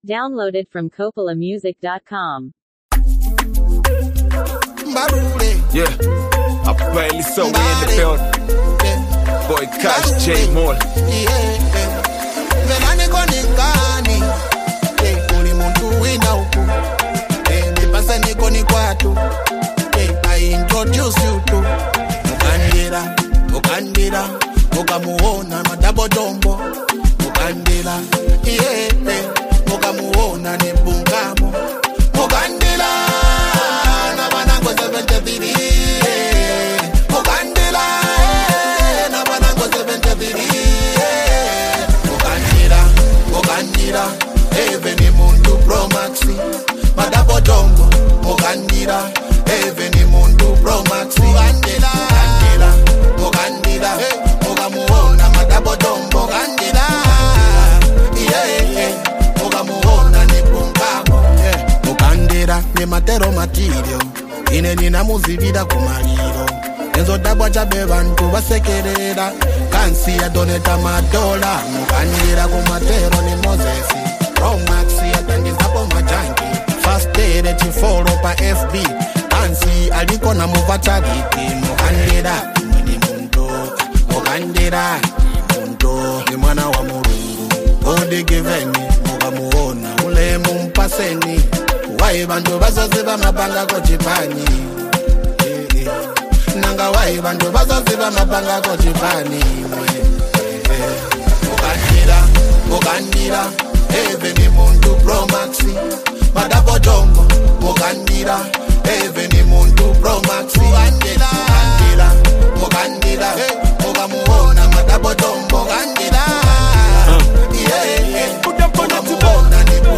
smooth and melodic approach
heartfelt vocals and soulful energy
signature street-inspired storytelling